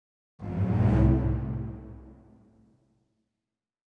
Suspense 3 - Stinger 1.wav